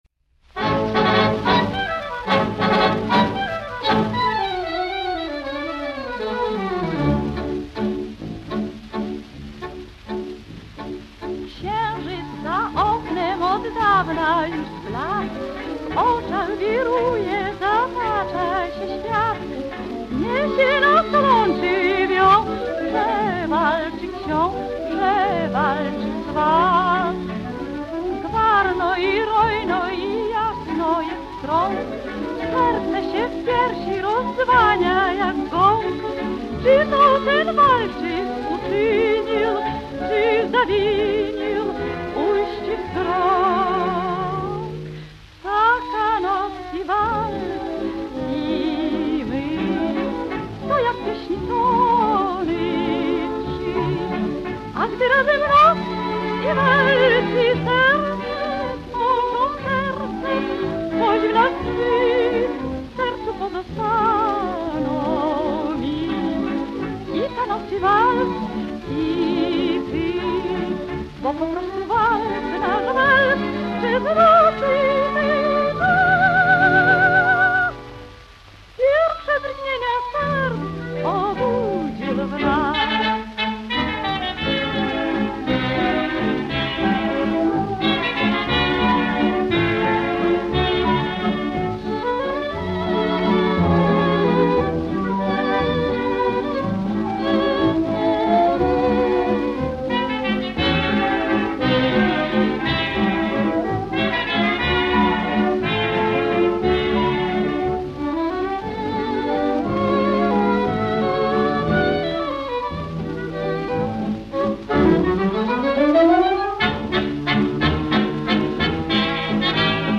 Witamy naszych słuchaczy w rytmie walca.